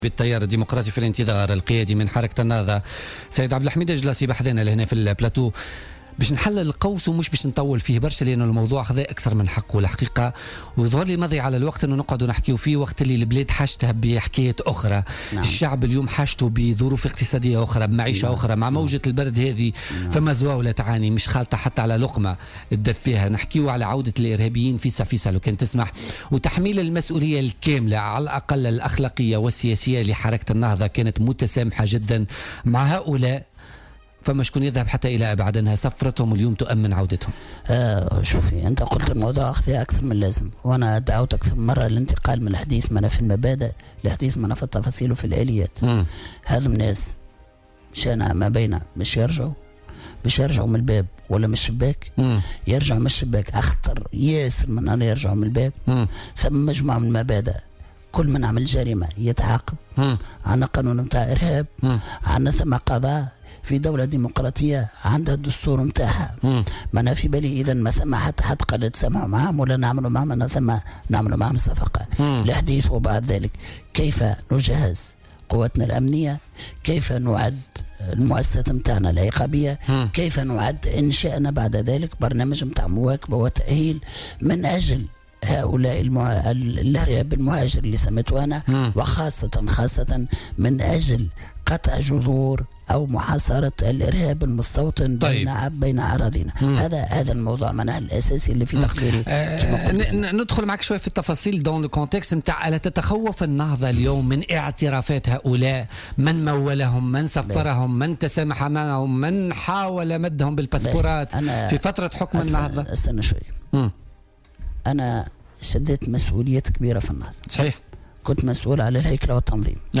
قال القيادي بحركة النهضة عبد الحميد الجلاصي ضيف بولتيكا اليوم الإثنين 9 جانفي 2017 إن حركة النهضة مع عودة الإرهابيين من بؤر التوتر ومع محاسبتهم وتطبيق القوانين عليهم والكشف عن الشبكات المتورطة في تسفيرهم على حد قوله.